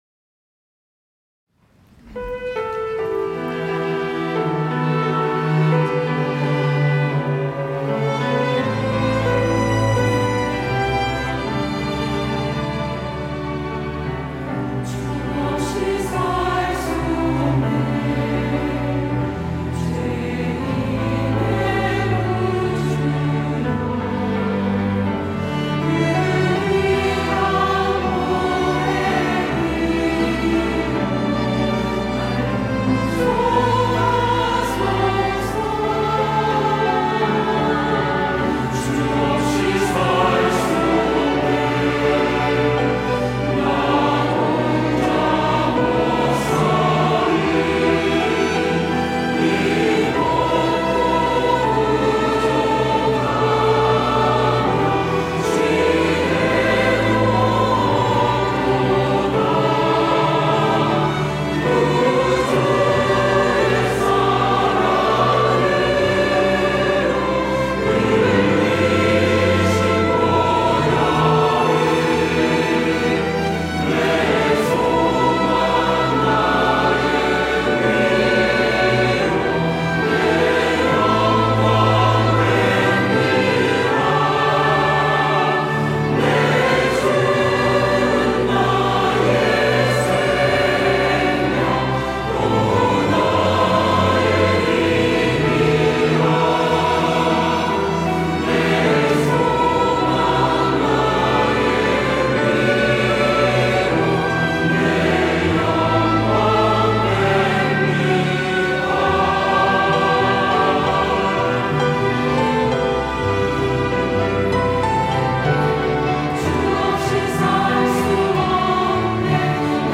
호산나(주일3부) - 주 없이 살 수 없네
찬양대